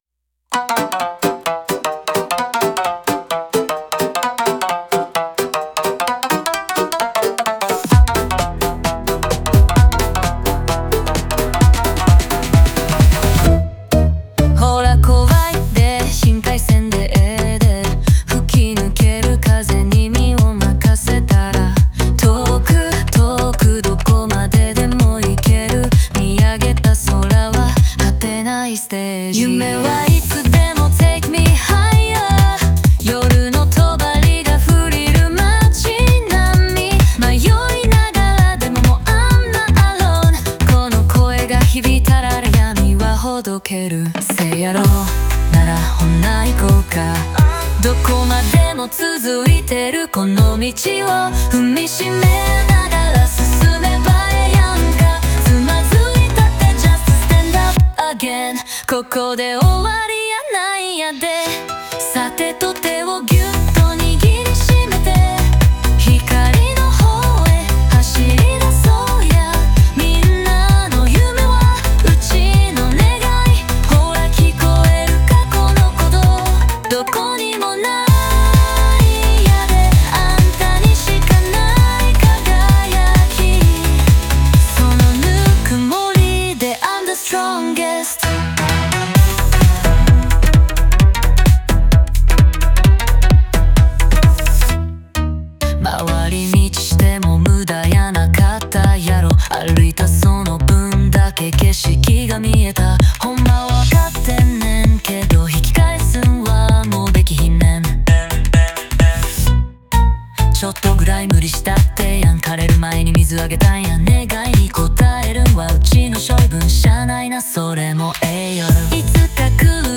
オリジナル曲♪
関西弁を使うことで親しみやすさを出し、英語のフレーズがアクセントとなってリズム感を生んでいる。